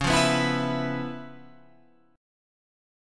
C#7#9 chord